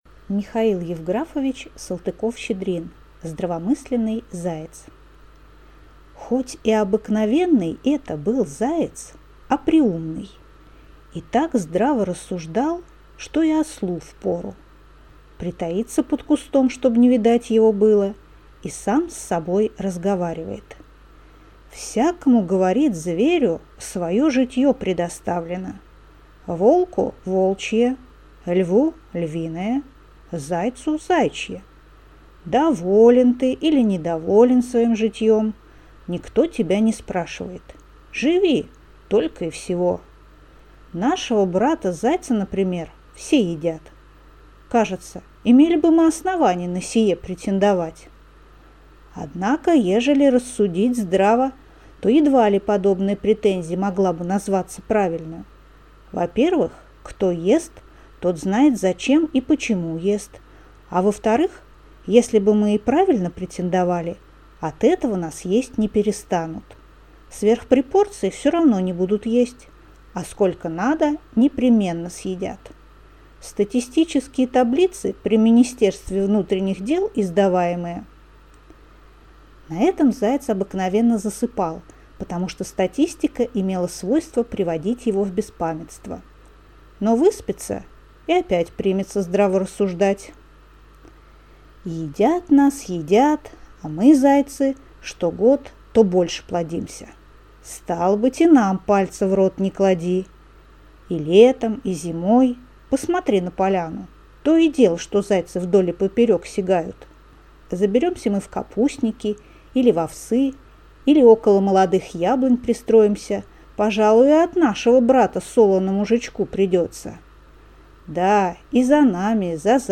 Аудиокнига Здравомысленный заяц | Библиотека аудиокниг